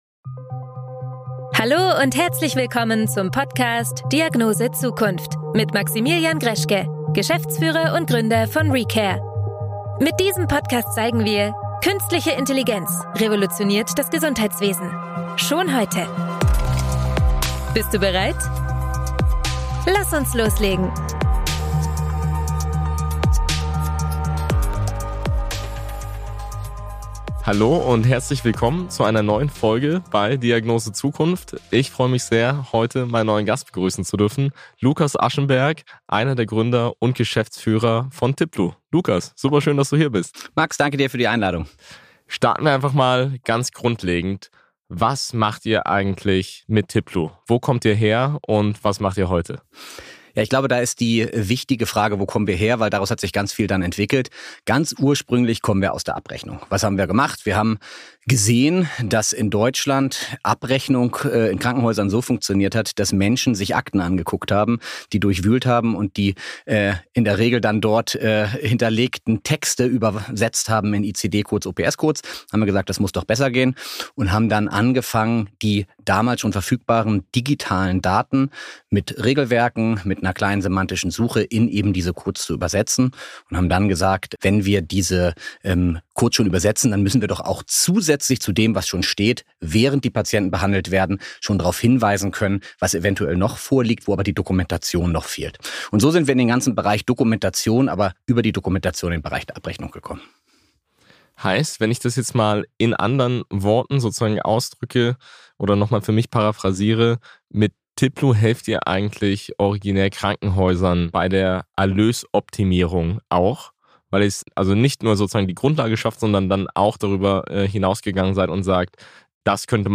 Automatisierung als Ausweg: Ein Blick auf die Zukunft der Klinik-IT – Interview